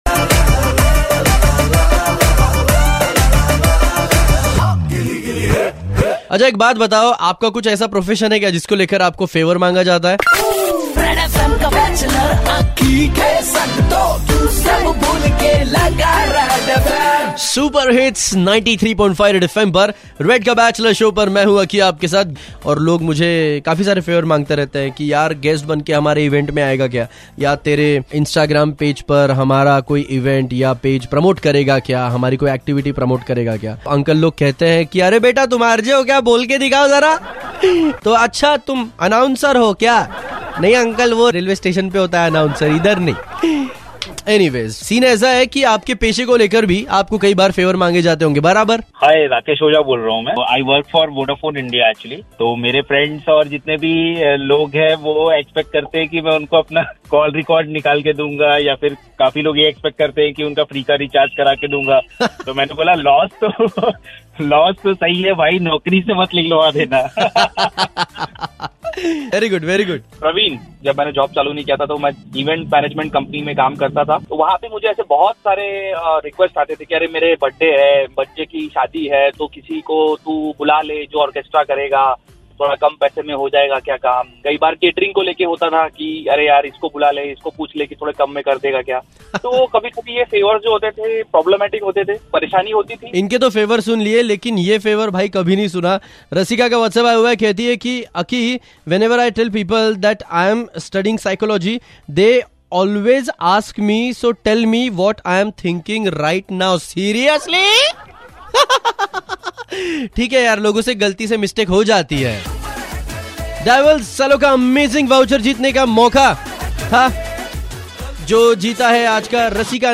even listners shared their experiences like this !!